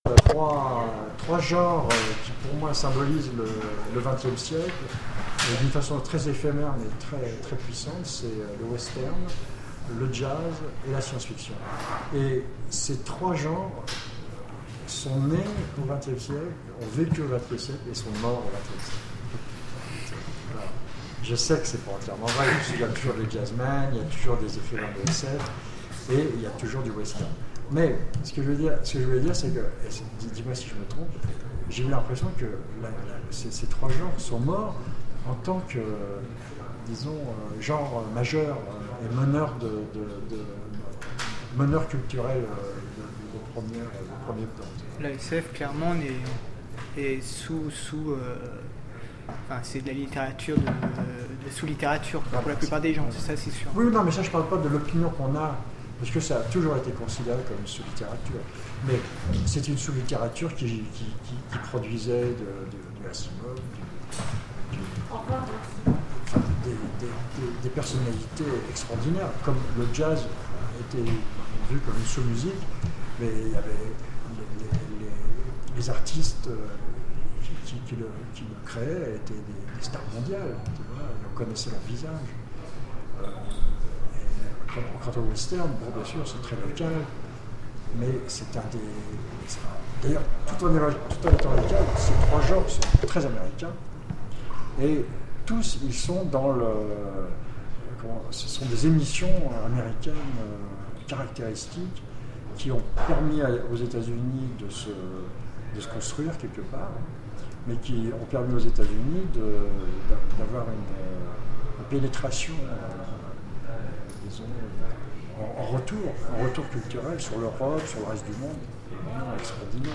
A l'occasion de son exposition à la Fondation Cartier, nous avons participé à une interview collective de Moebius.